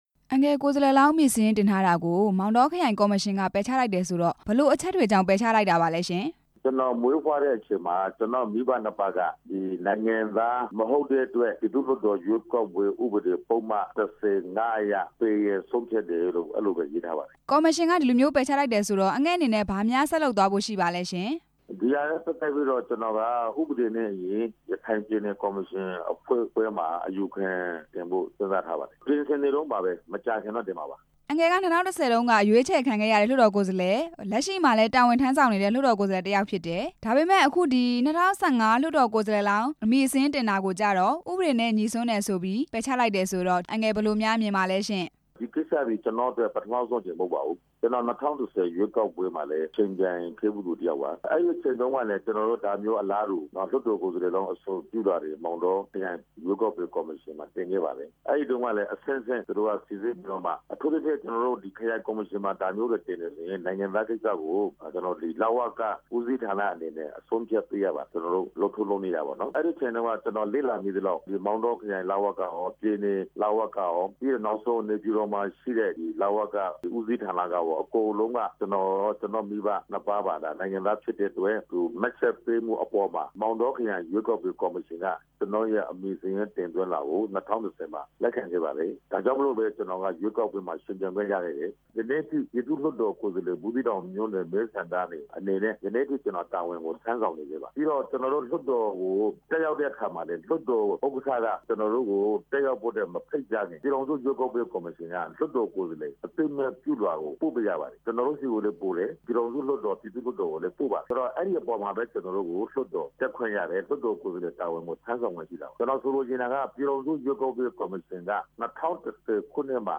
ကိုယ်စားလှယ်လောင်းအဖြစ်ကနေ ပယ်ချလိုက်တဲ့ ဦးရွှေမောင်နဲ့ မေးမြန်းချက်